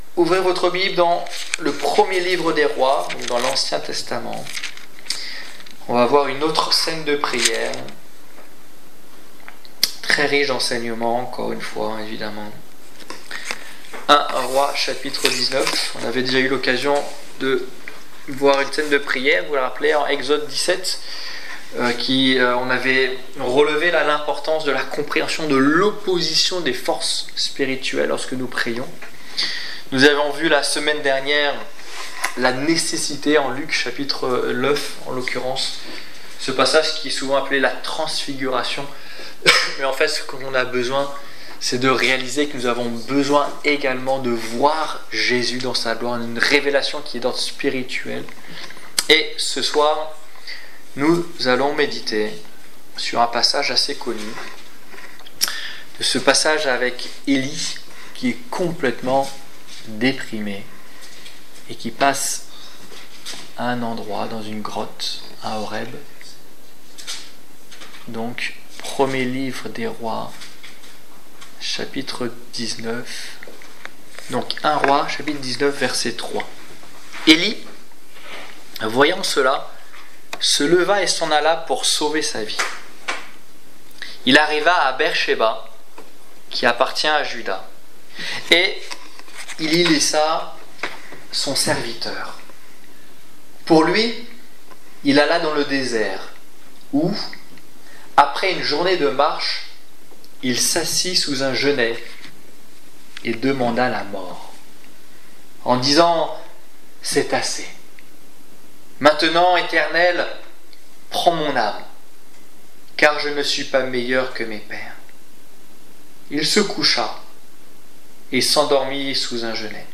Étude biblique du 23 décembre 2015